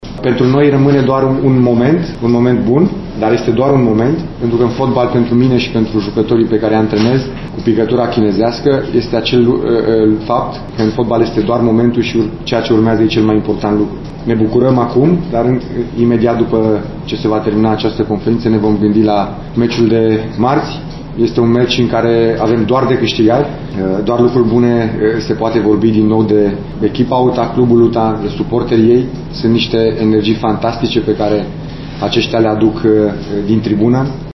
În același timp, Badea a atras atenția că deja toată lumea trebuie să se gândească la viitorul joc, marți, cu CFR Cluj:
Badea-pt-noi-e-un-moment-bun-dar-vine-jocu-cu-CFR.mp3